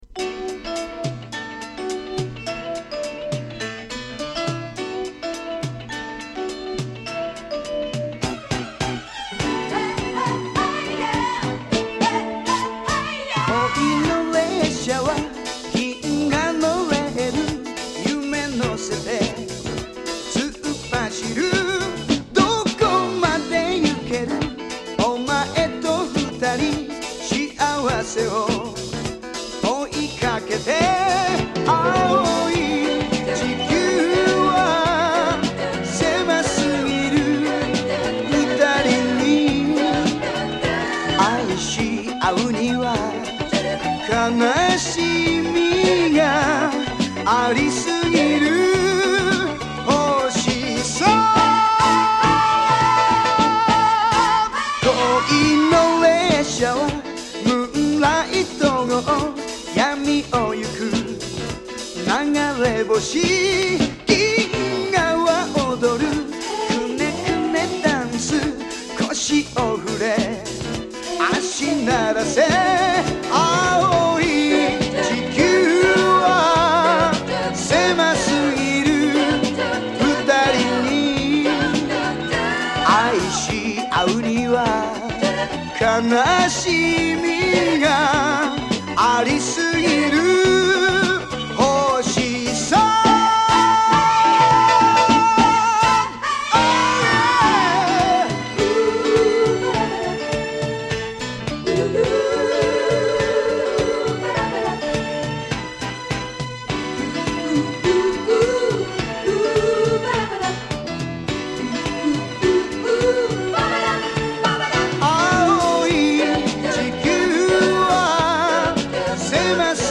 和製フィリーソウル最高傑作